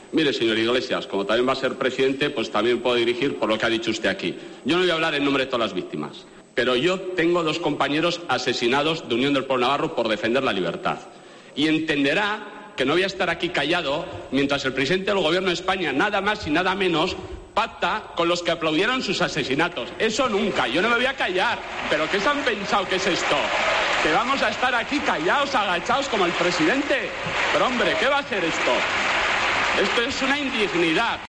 Durante su intervención ante el Pleno que someterá este martes a votación definitiva la investidura de Pedro Sánchez, el diputado navarro ha asegurado que la formación abertzale sigue pensando que el terrorismo de ETA "mereció la pena" y no ha condenado ningún atentado de esa banda.